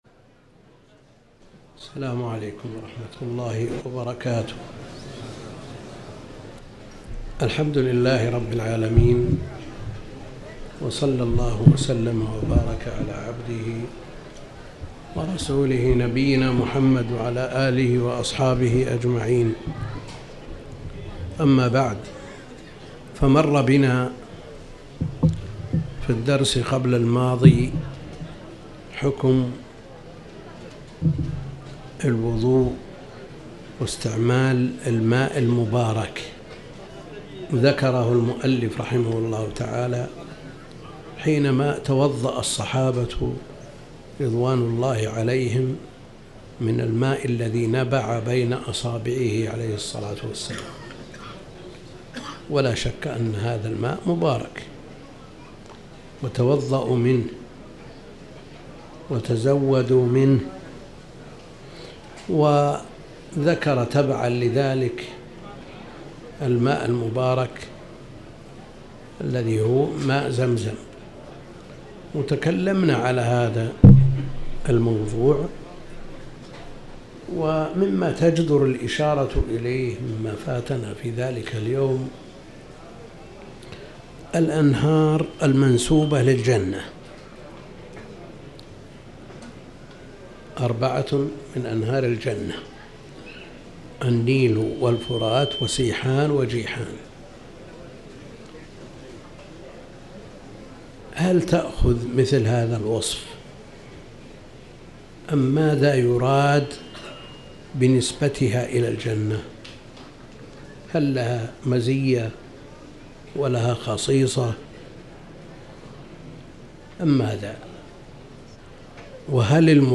تاريخ النشر ٩ ذو القعدة ١٤٤٠ هـ المكان: المسجد الحرام الشيخ: فضيلة الشيخ د. عبد الكريم بن عبد الله الخضير فضيلة الشيخ د. عبد الكريم بن عبد الله الخضير أحاديث وأحكام